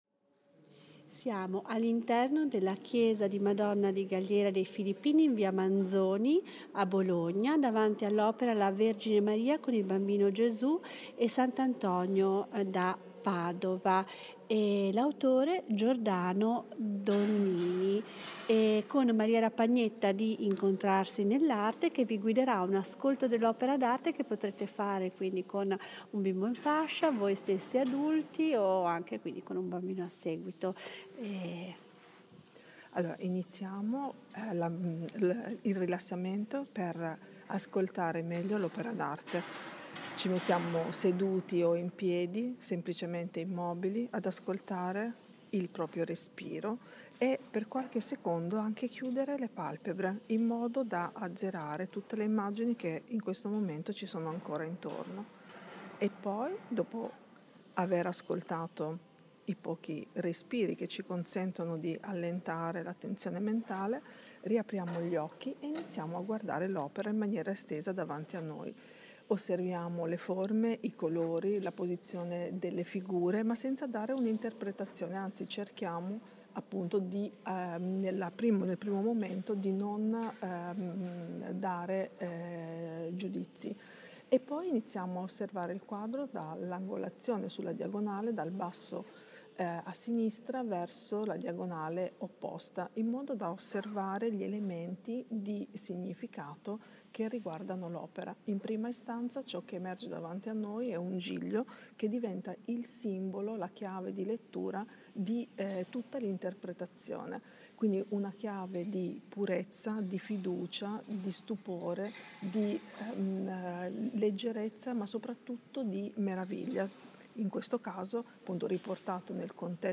un estratto della visita di ascolto dell’opera d’arte